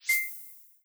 Success13.wav